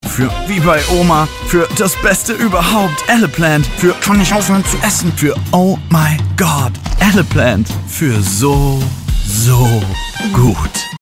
Sprechprobe: Werbung (Muttersprache):
Eleplant_Werbung.mp3